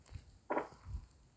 Carpintero Lomo Blanco (Campephilus leucopogon)
Nombre en inglés: Cream-backed Woodpecker
Localidad o área protegida: Cerro San Javier
Condición: Silvestre
Certeza: Fotografiada, Vocalización Grabada
Carpintero-lomo-blanco.mp3